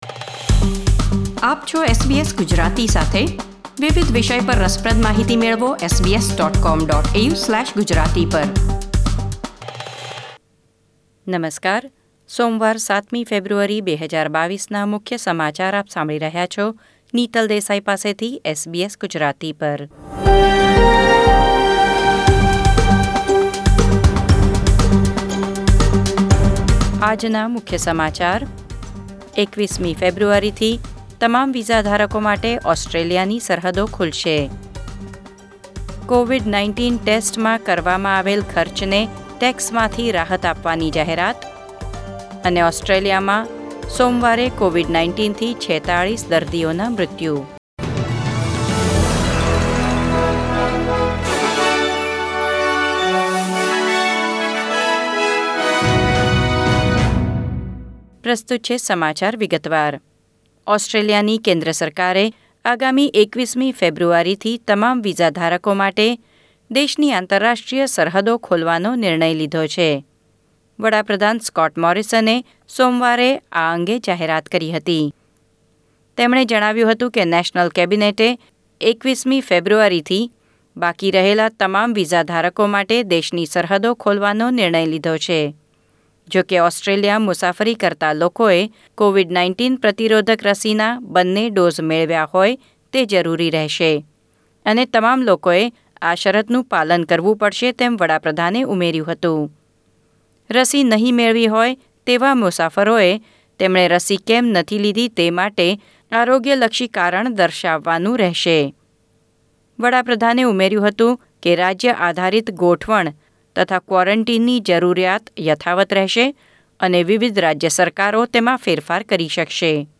SBS Gujarati News Bulletin 7 February 2022